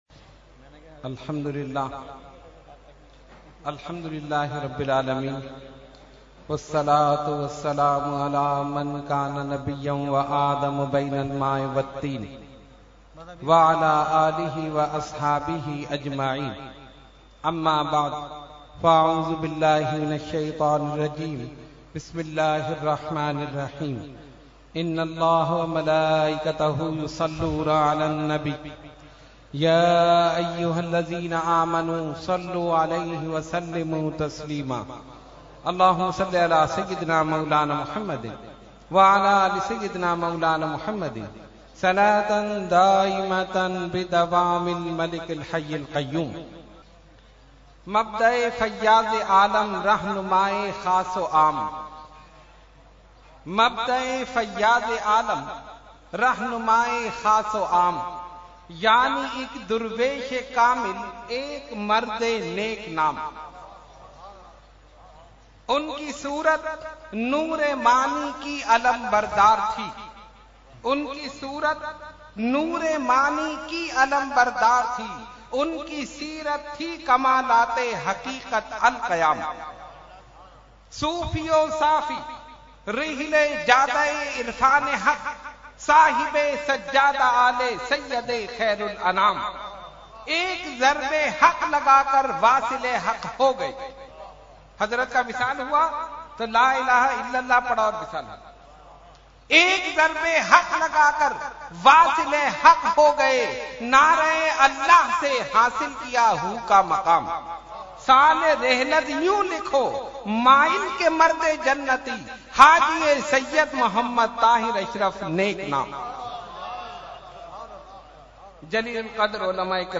Category : Speech | Language : UrduEvent : Urs Qutbe Rabbani 2018